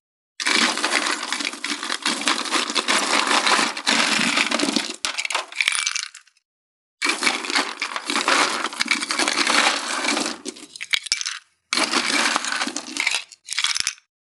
43.氷をコップに入れる【無料効果音】
ASMRコップ効果音
ASMR